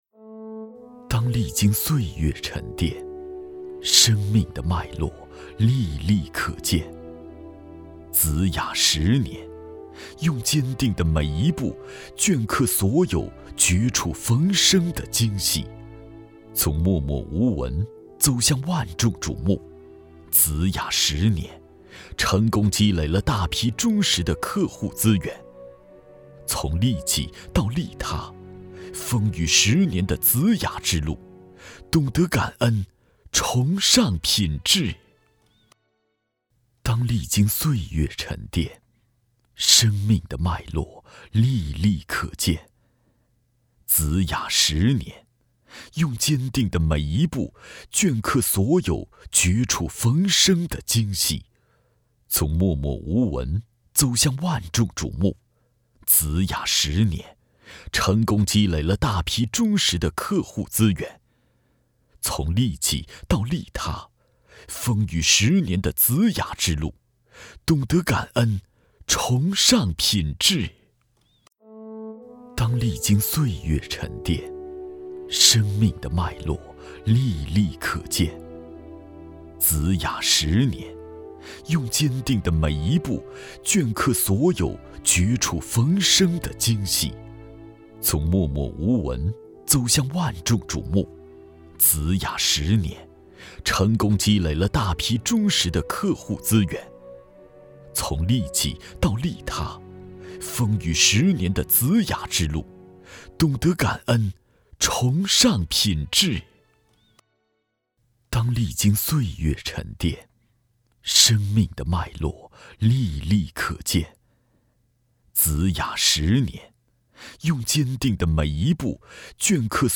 职业配音员全职配音员浑厚大气 年轻感
• 男S346 国语 男声 宣传片—企业专题片—真挚情感 大气浑厚磁性|沉稳